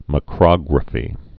(mə-krŏgrə-fē)